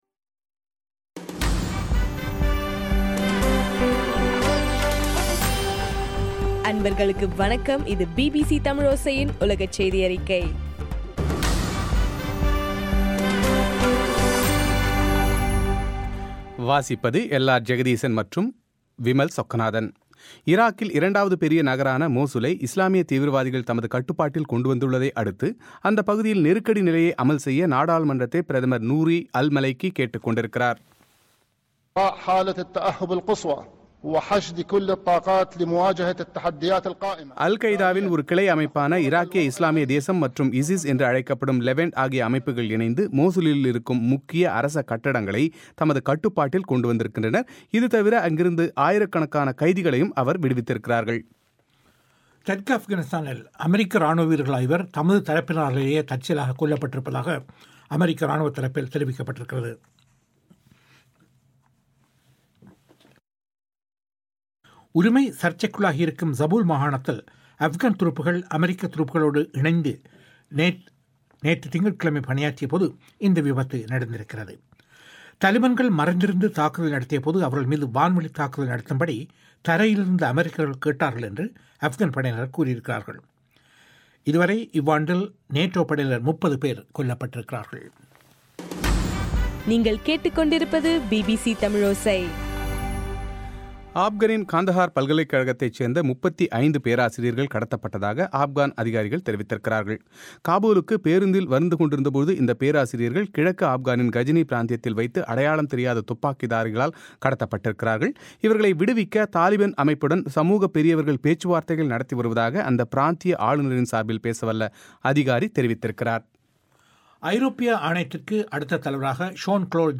இன்றைய ( ஜூன் 10) பிபிசி தமிழோசை செய்தியறிக்கை